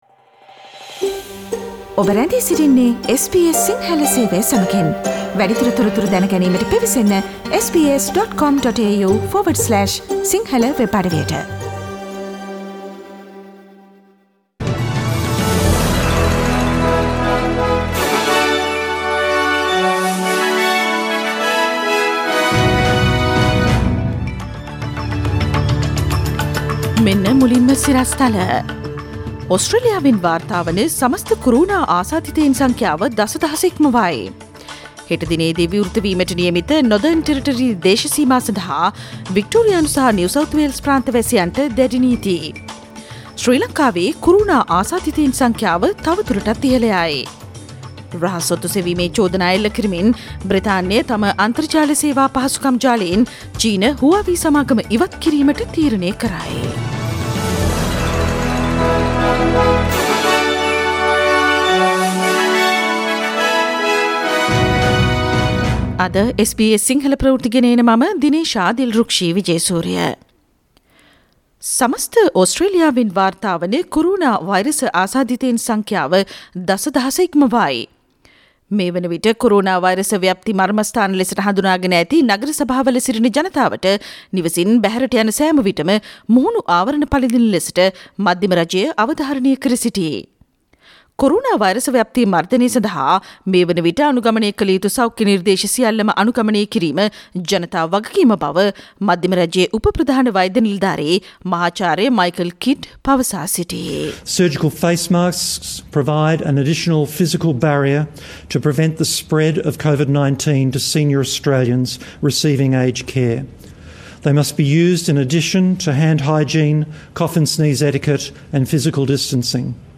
Today’s news bulletin of SBS Sinhala radio – Thursday 16 July 2020.